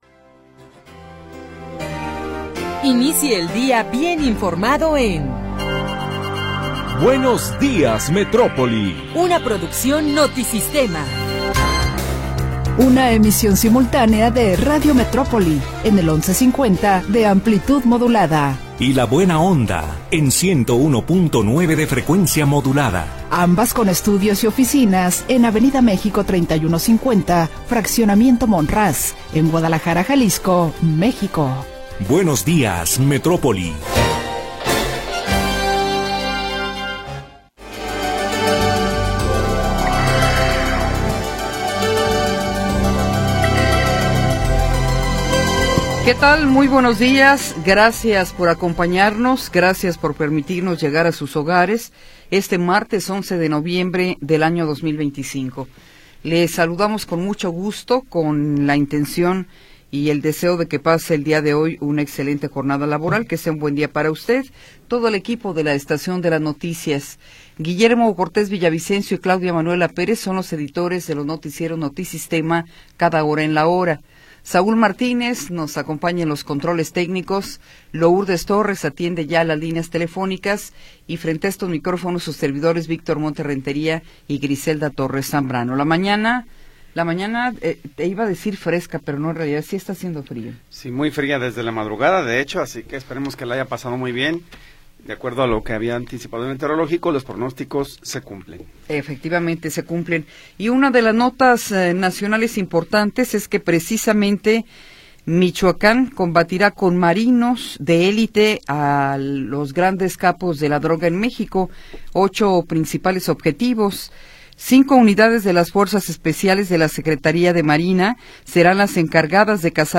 Información oportuna y entrevistas de interés